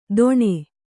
♪ doṇe